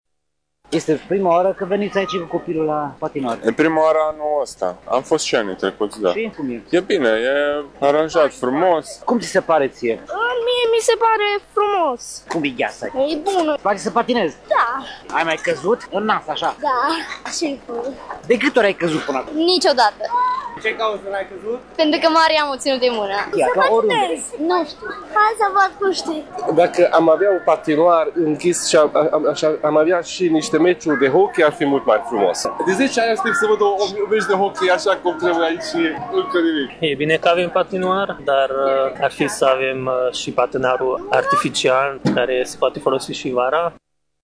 Cei prezenţi astăzi la patinoar, mari şi mici, s-au decarat mulţumiţi atât de calitatea gheţii cât şi de preţuri, de intrare şi de închiriere a patinelor.